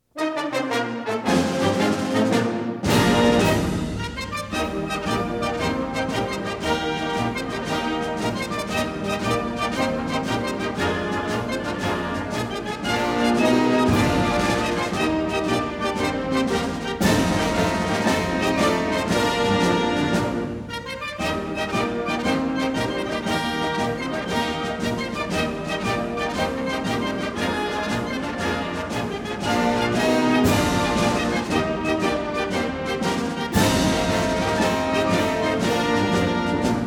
1957 stereo recording